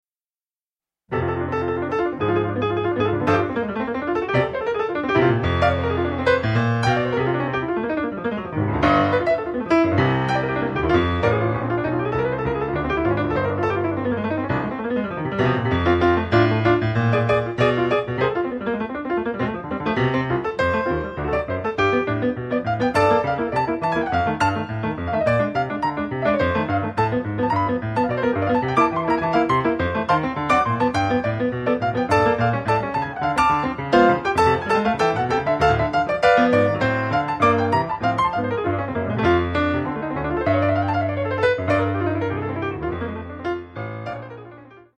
Bon, les micros etant branchés...de quoi repiquer un peu....mon morceau fetiche, un peu inspiré d'oscar peterson...mais vous ne m'en voudrez pas :)